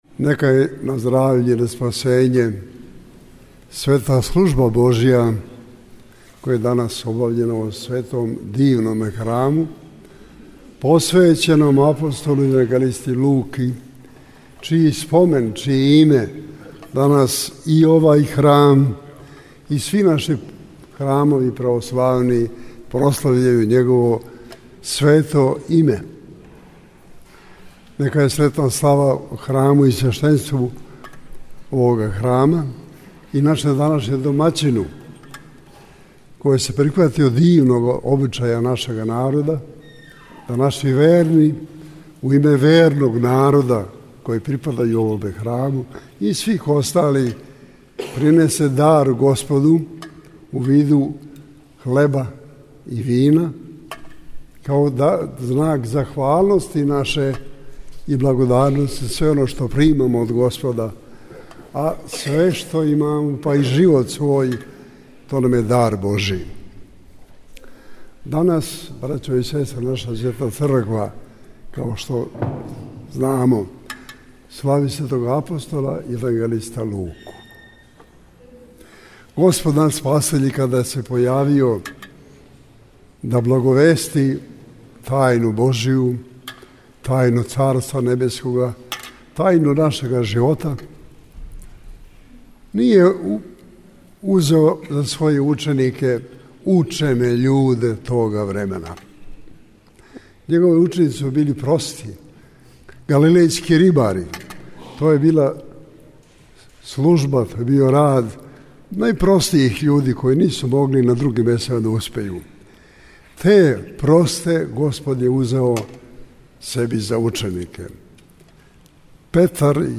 Његова Светост Патријарх српски Г. Иринеј служио је на празник светог апостола Луке, 31. октобра 2019. године, Свету Архијерејску Литургију у храму посвећеном овом светутељу у београдском насељу Кошутњак.
После Свете Литургије и резања славског колача Свјатјејши Патријарх је поучио свештенство и верни народ својом надахнутом беседом у којој је истакао важност нашег угледања на светитеље Божије, које нам је као узор сам Господ пројавио. Свјатјејши је додао да је Господ себи за ученике узео обичне рибаре, људе који су били најпростијег занимања и образовања тога доба.